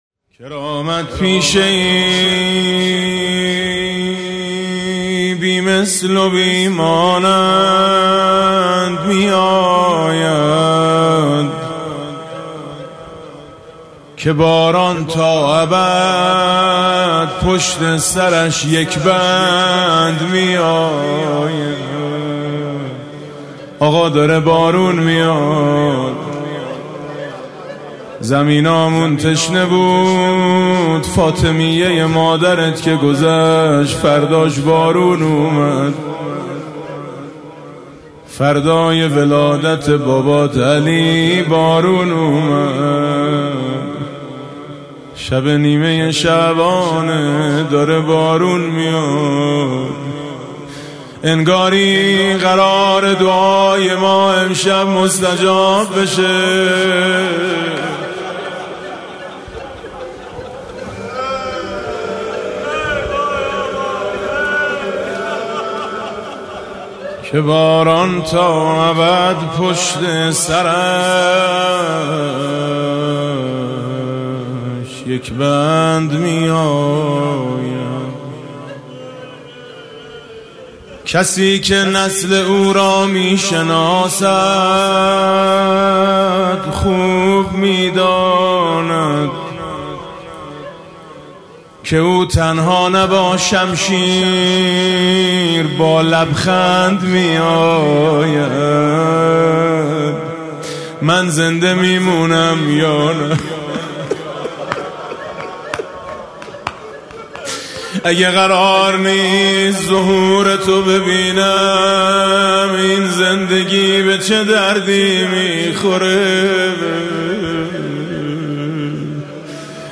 [آستان مقدس امامزاده قاضي الصابر (ع)]
مناسبت: قرائت دعای کمیل در شب نیمه‌ شعبان
با نوای: حاج میثم مطیعی